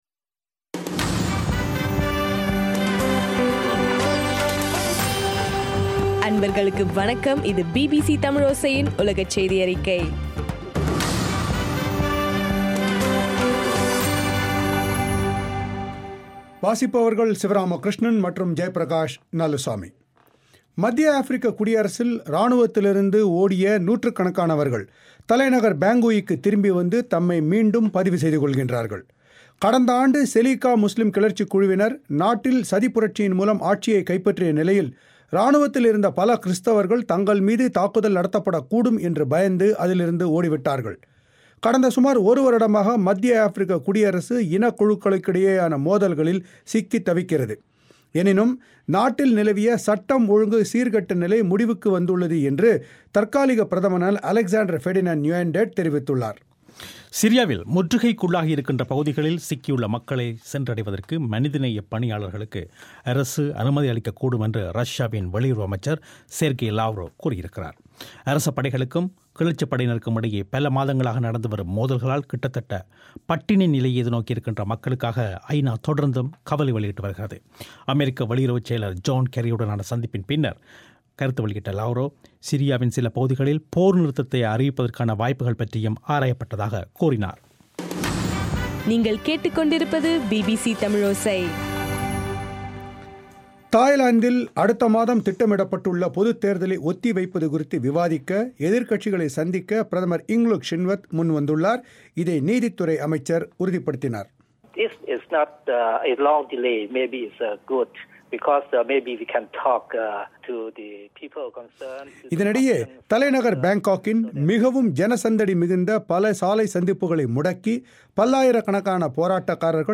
இலங்கையின் சக்தி எப்எம் வானொலியில் ஒலிபரப்பான பிபிசி தமிழோசையின் உலகச் செய்தியறிக்கை